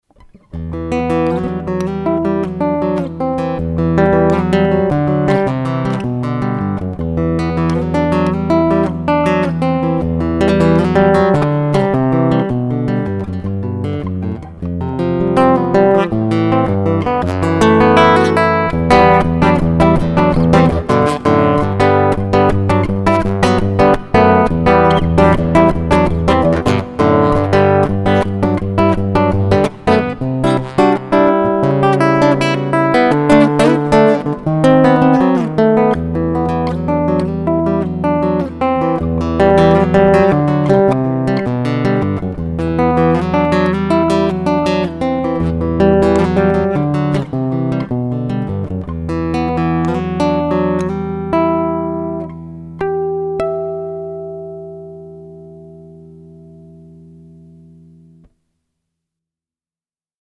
Yamaha SLG 130NWYamaha SLG 130NW - это бескорпусная и потому бесшумная (silent) электроакустическая классическая гитара с нейлоновыми струнами.
Примеров звучания Yamaha SLG 130NW достаточно много на Ютуб, но и я сделал запись с линейного выхода.
По-моему, производителю удалось сгладить пьезо-эффект, но звук при этом получился достаточно безжизненный, туго реагирующий на динамику игры - звуки приходится «высекать» из инструмента.
Соло 1,42 Мб
После записи звук скомпрессирован и разбавлен ревером.